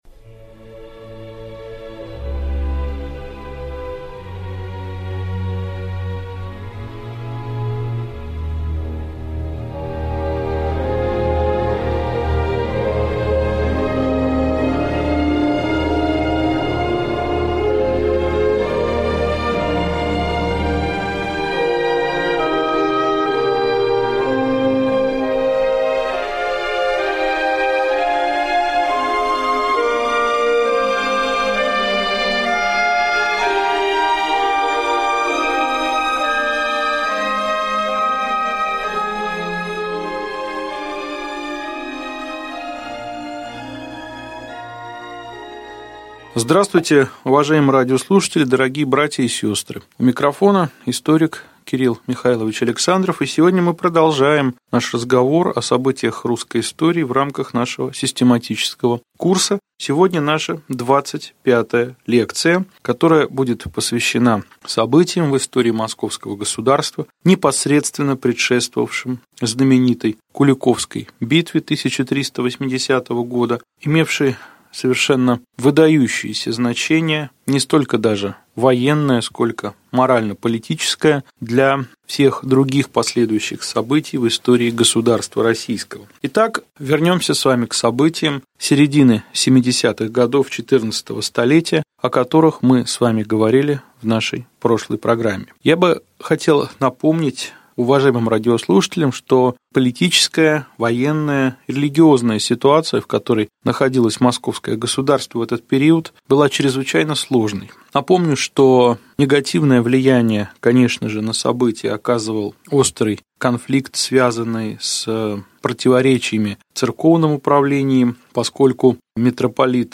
Аудиокнига Лекция 24. Сражения русских с татарами до Куликовской битвы | Библиотека аудиокниг